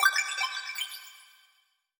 Riochet Notification.wav